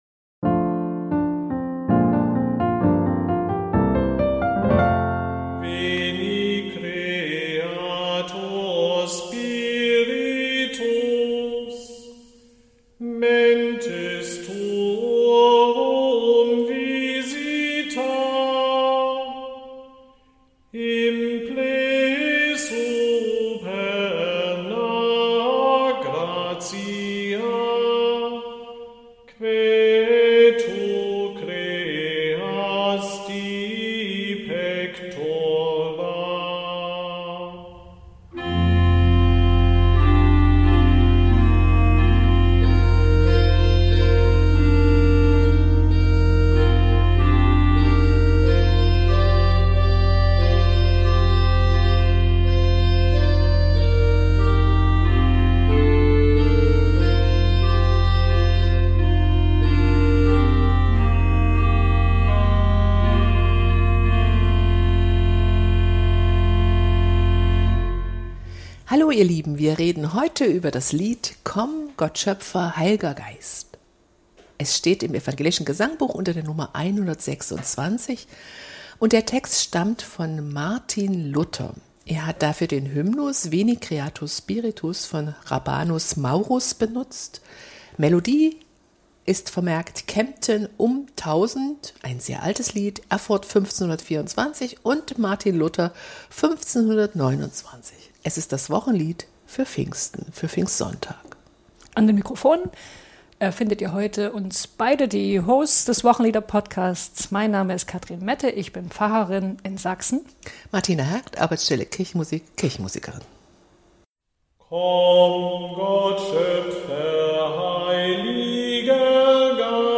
Beschreibung vor 10 Monaten Auch wenn der Text so fremd wirkt wie die Melodie (in der Tonart F-mixolydisch) sind die beiden Hosts des Wochenliederpodcasts ziemlich angetan von diesem Lied. Martin Luther hat es auf der Grundlage des Hymnus „Veni creator spiritus“ geschrieben, den Hrabanus Maurus Anfang des 9. Jahrhunderts verfasst hat. Die Melodie ist fast genauso alt.